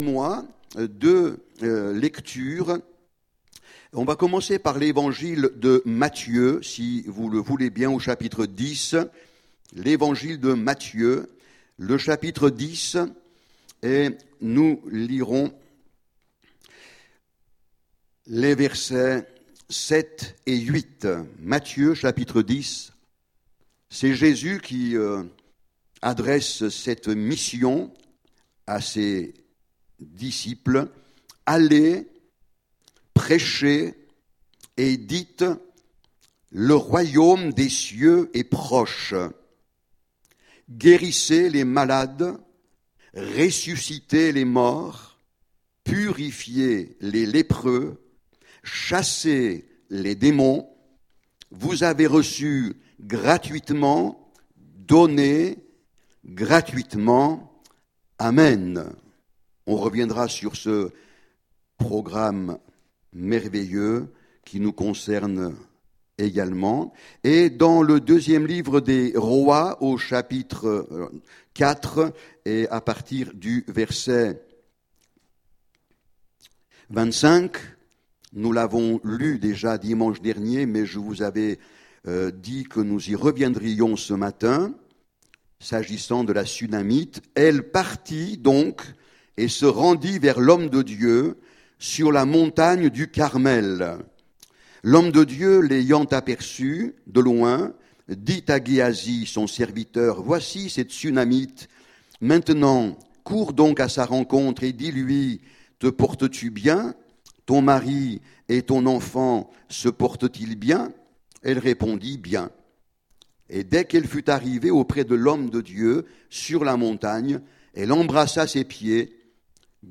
Aller plus loin avec le Seigneur Prédicateur